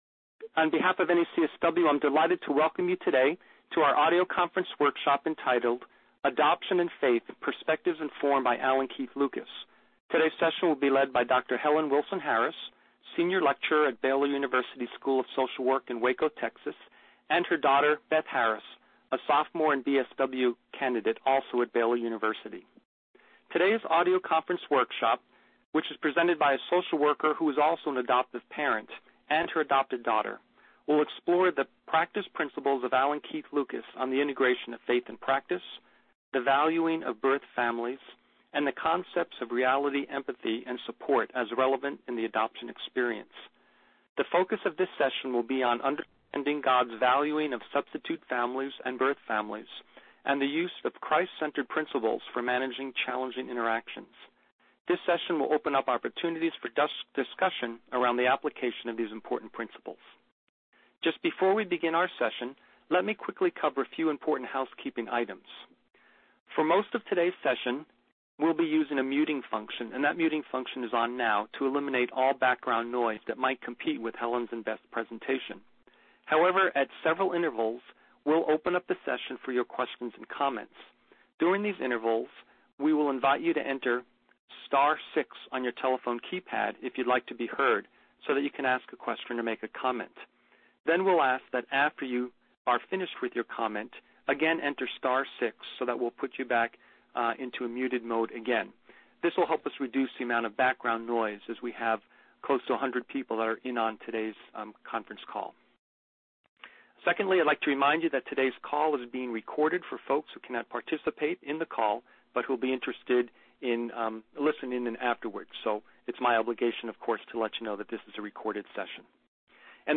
Training description: Presented by a social worker who is an adoptive parent and a social work student (her adoptive daughter).